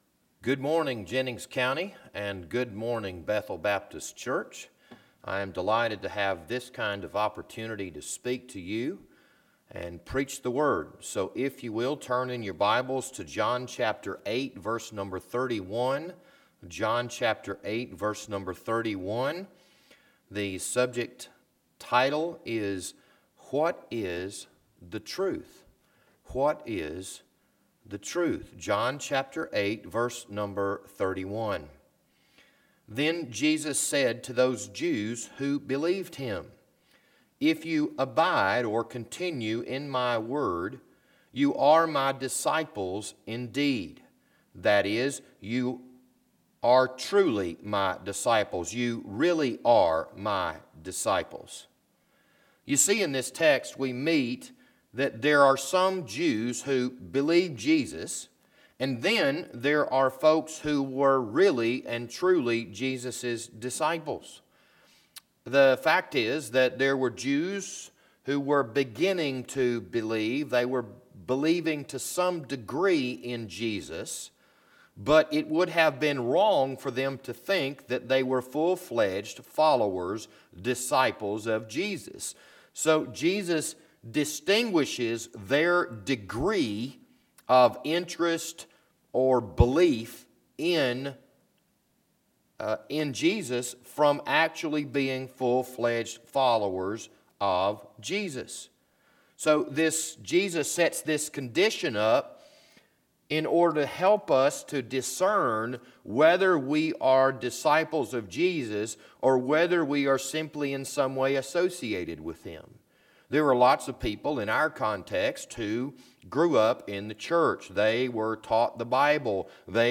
This Sunday morning sermon was recorded on March 22nd, 2020.